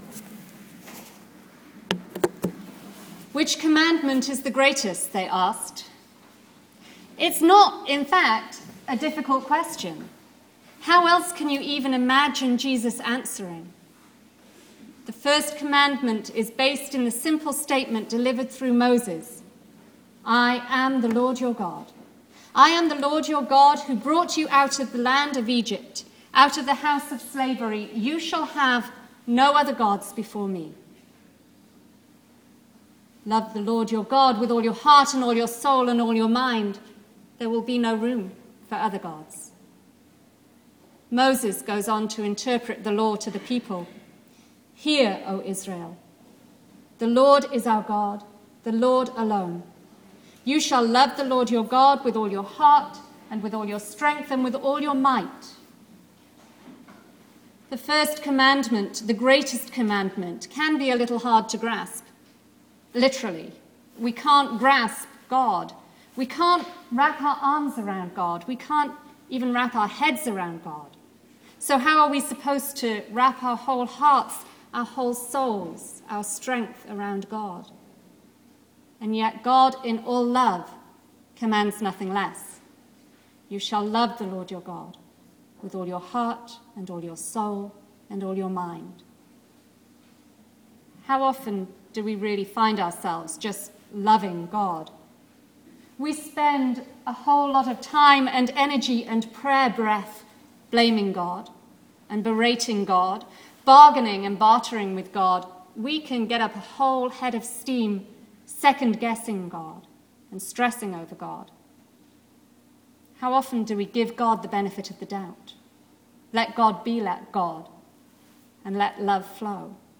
Audio from this morning's sermon
sermon.m4a